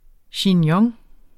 Udtale [ ɕinˈjʌŋ ]